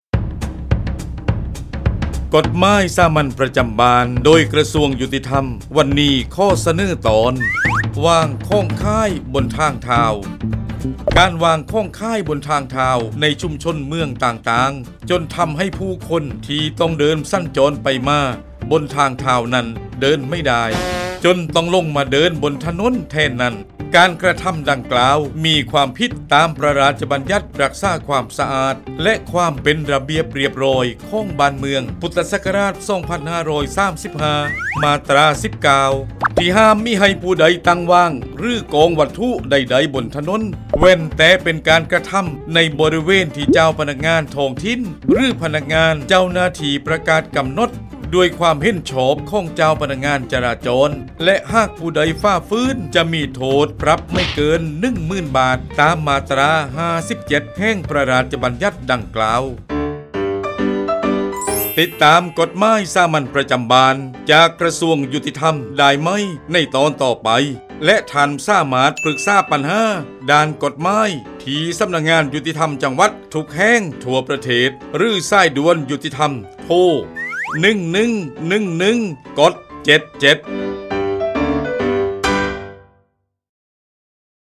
กฎหมายสามัญประจำบ้าน ฉบับภาษาท้องถิ่น ภาคใต้ ตอนวางของขายบนทางเท้า
ลักษณะของสื่อ :   คลิปเสียง, บรรยาย